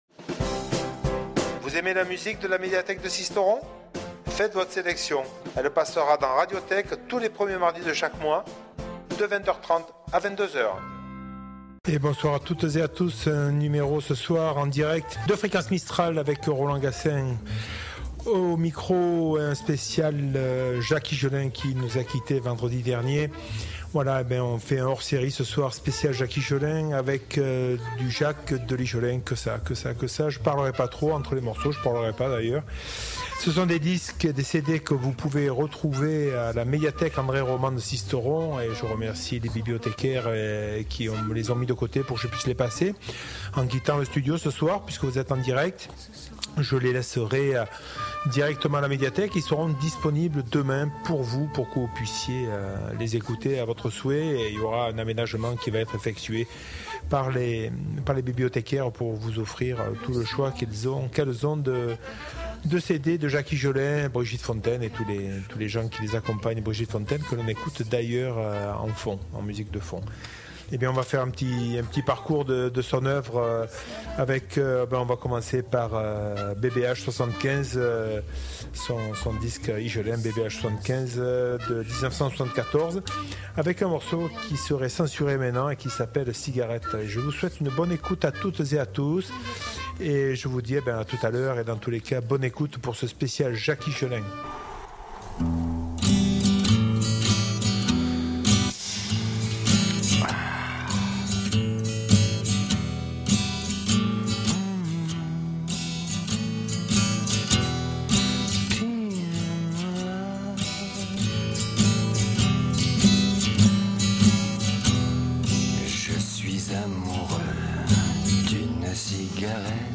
Vous et seulement vous avez le pouvoir de choisir la programmation musicale de cette émission, une première dans le monde radiophonique !